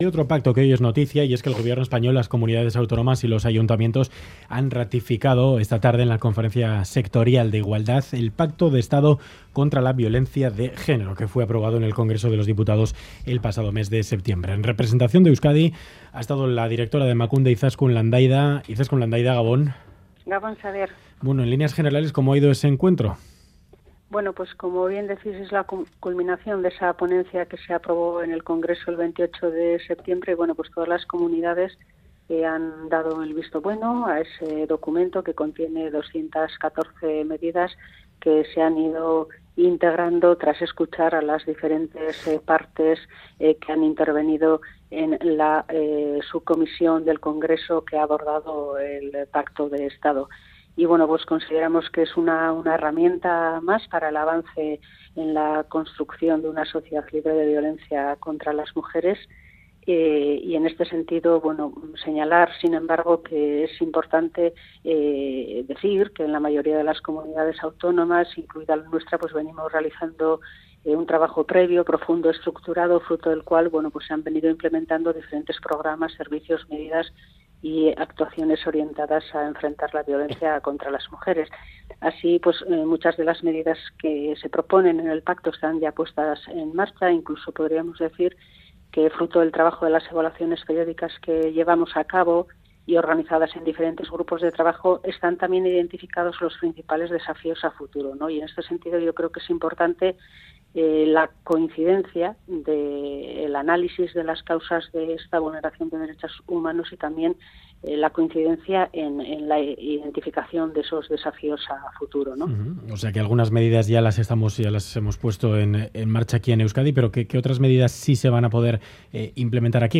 Audio: Entrevista a la directora de Emakunde, Izaskun Landaida, tras el acuerdo alcanzado, por unanimidad, por el Pacto de Estado contra la violencia de género.